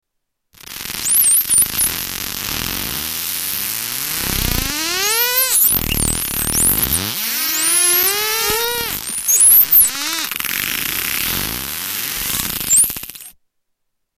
Common dolphin echolocation
Category: Animals/Nature   Right: Personal